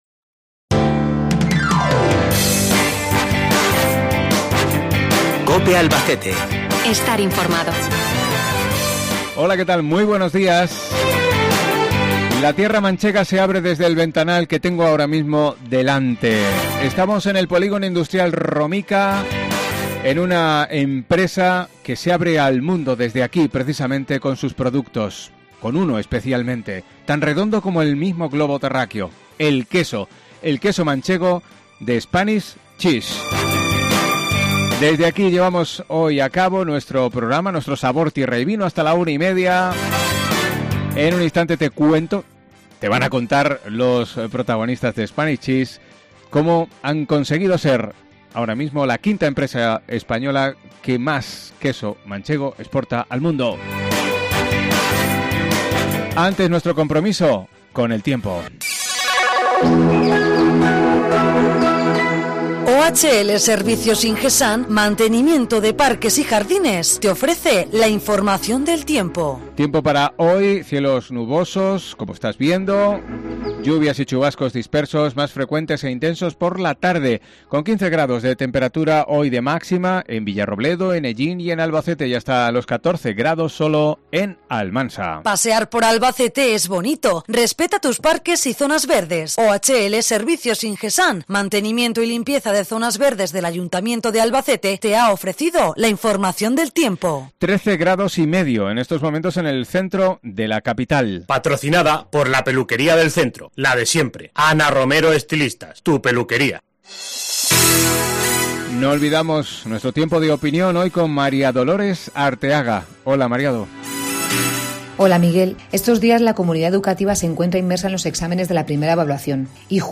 AUDIO: Programa que realizamos desde esta empresa manchega presente en los cinco continentes con el queso manchego como bandera